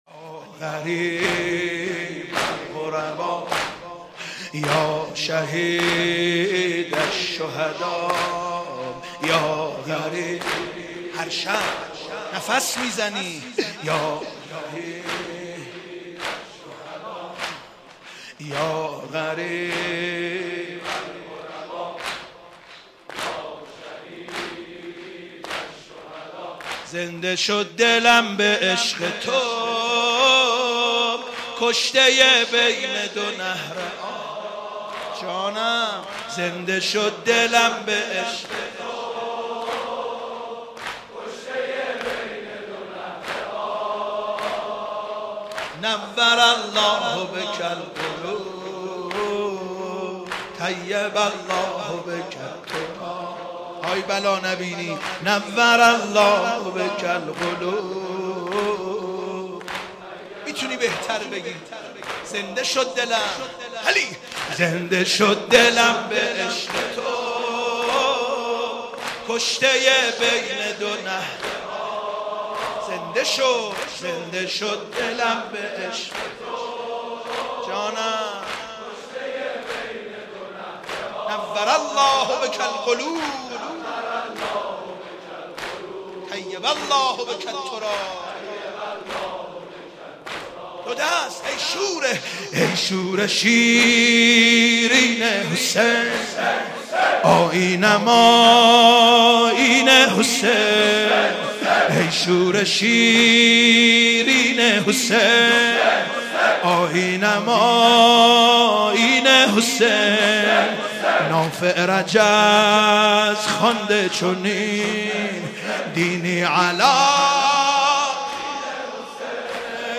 شب پنجم محرم97 تهران مسجد امیر